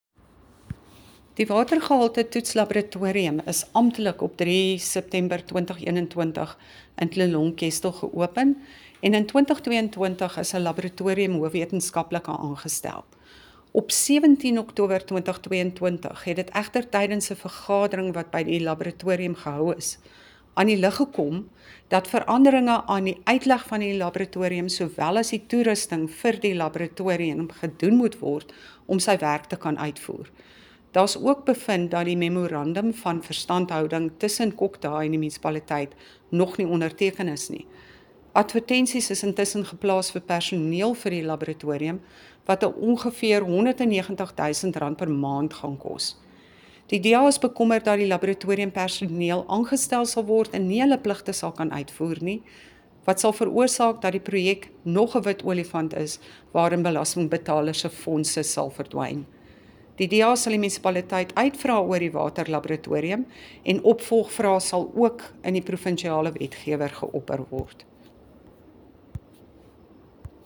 Afrikaans soundbites by Cllr Bea Campbell-Cloete